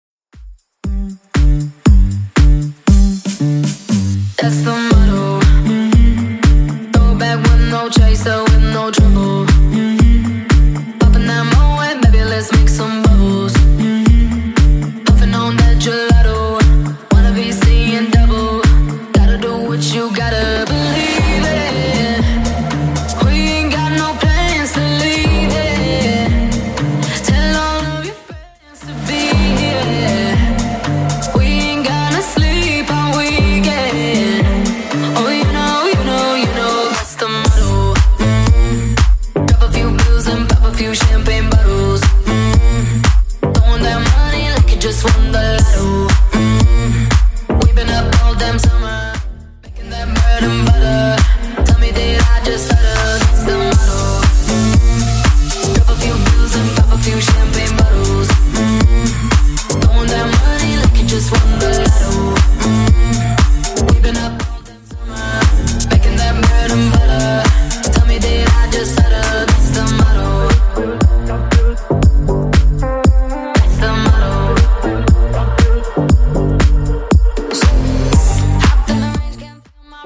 BPM: 118 Time